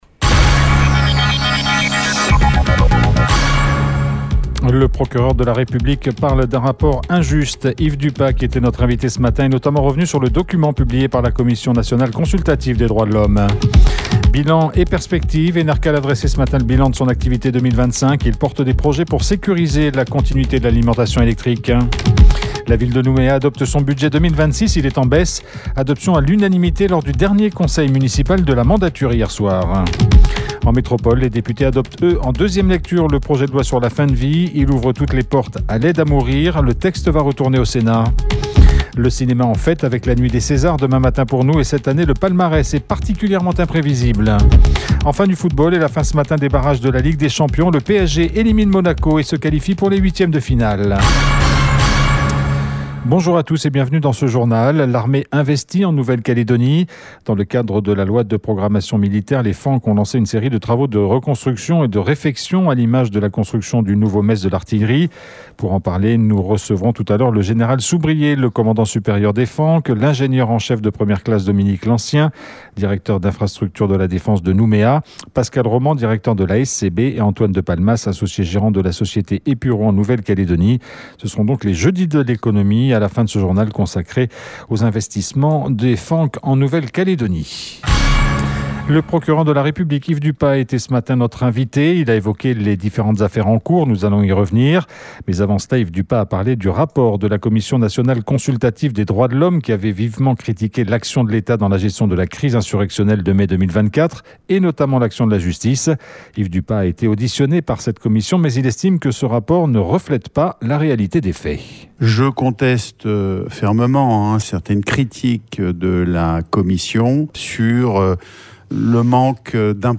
Un narratif aveuglant et inquiétant, qui est alimenté par la désinformation : c’est le commentaire du procureur de la République Yves Dupas, qui était notre invité ce matin, sur le rapport de la commission nationale consultative qui a vivement critiqué l’action de l’Etat dans la gestion des émeutes de mai 2024 et notamment l’action de la justice. Yves Dupas est également revenu sur l’affaire qu’il a rendu publique hier soir. 2 policiers nationaux sont convoqués devant le tribunal correctionnel le 7 juillet, pour des faits qui remontent à Novembre dernier lors de l’interpellation d’un mineur à Magenta.